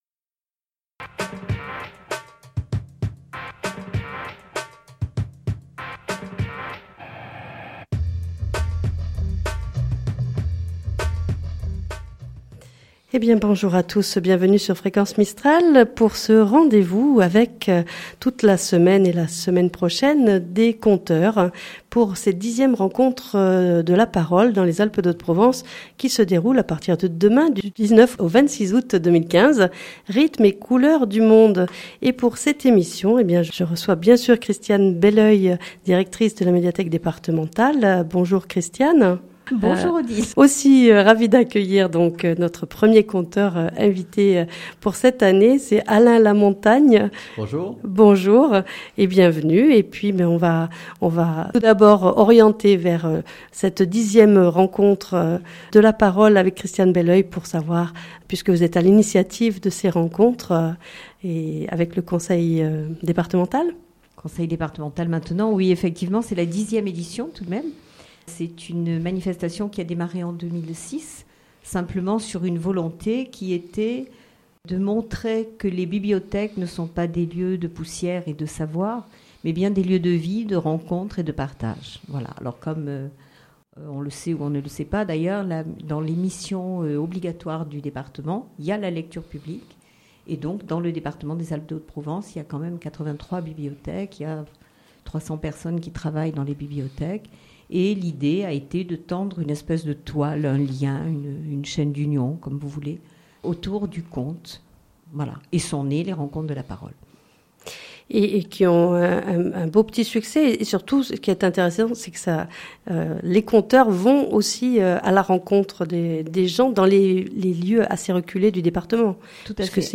Nous avons le plaisir de l'accueillir en direct sur notre antenne